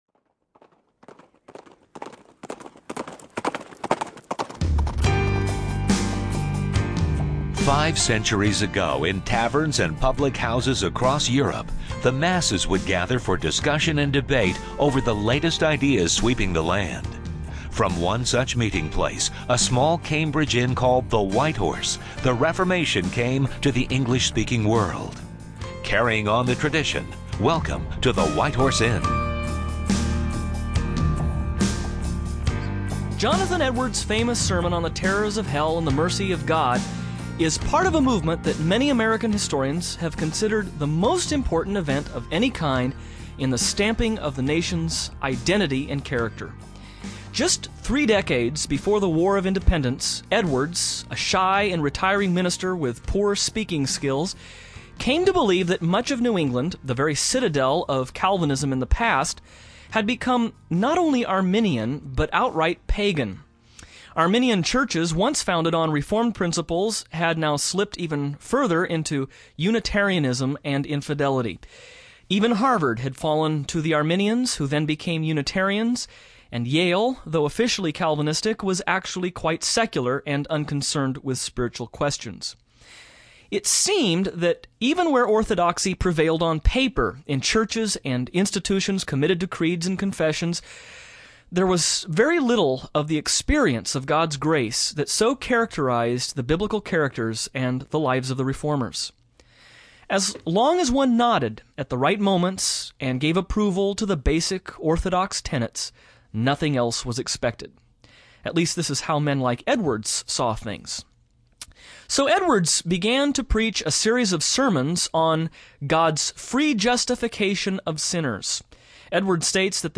Who were Jonathan Edwards and George Whitfield, and what did they believe about the doctrines of grace? How did their views differ from the later revivalist, Charles Finney? On this program this hosts will discuss the strengths and weaknesses of America’s First and Second Great Awakenings (originally aired 06-11-95).